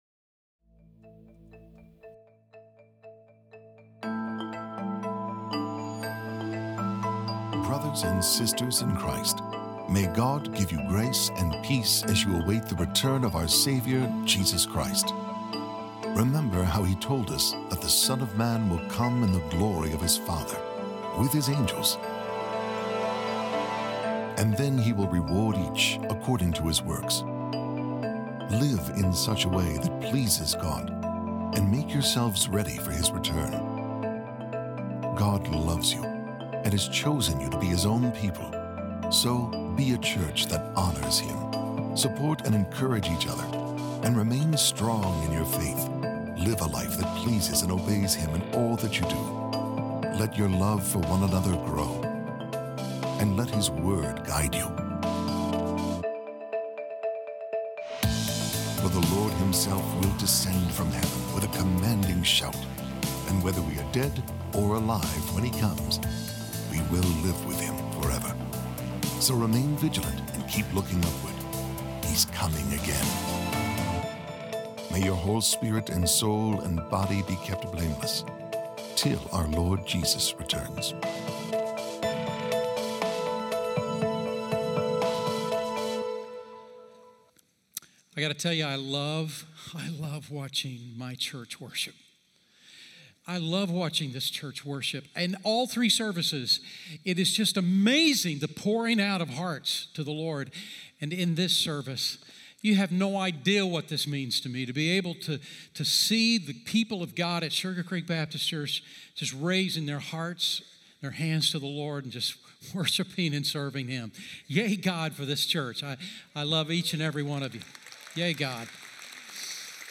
Here you can watch Sugar Creek sermons live online, or look back at previous sermons.